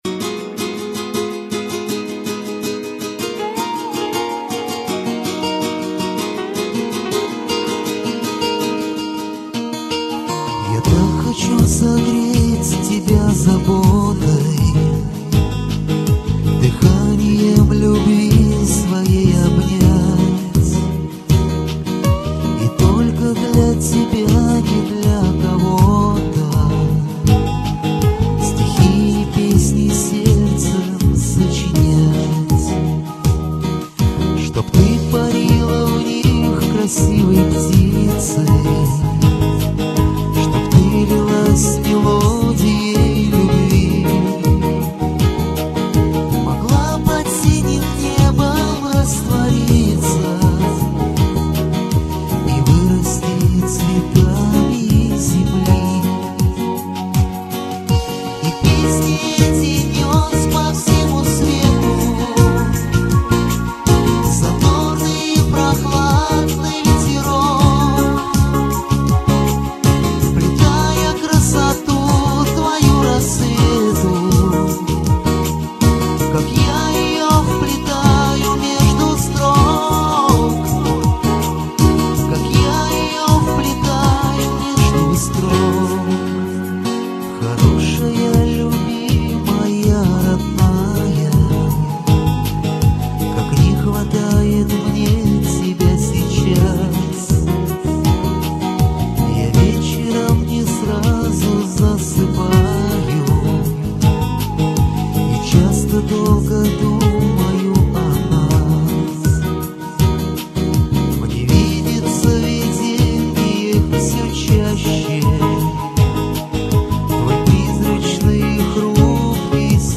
Очень приятная, нежно-лиричная песня!